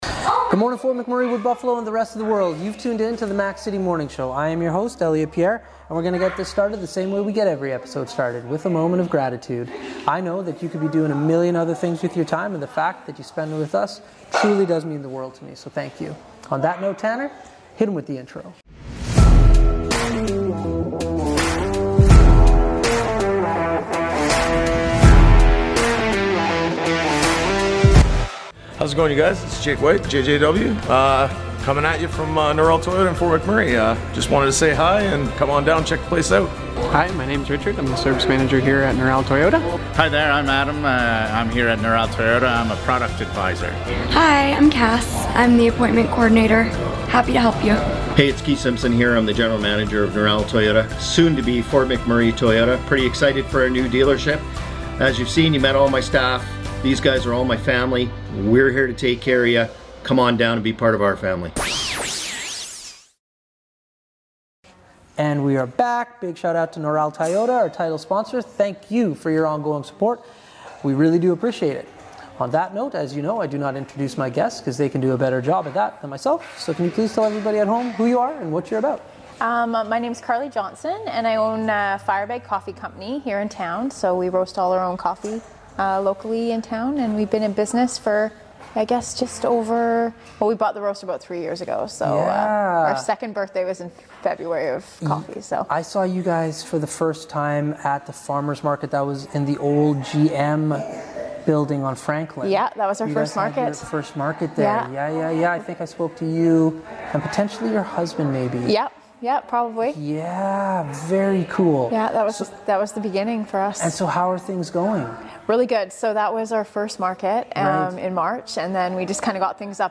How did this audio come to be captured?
On location again today